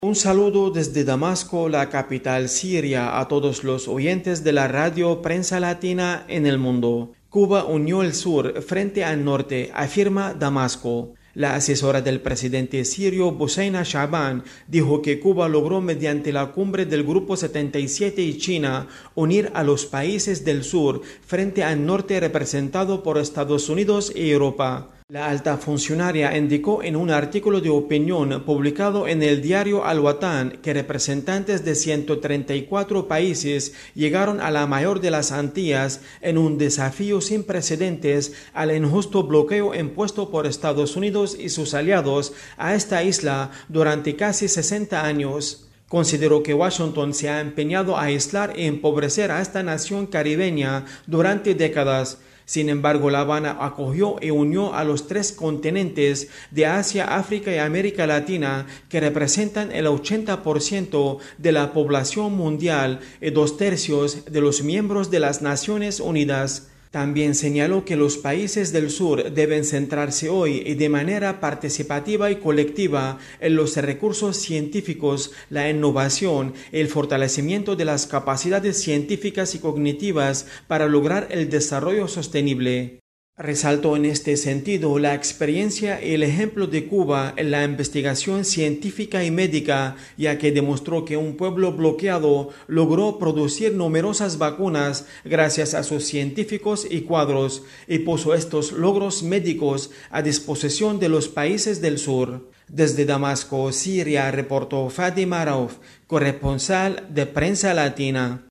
desde Damasco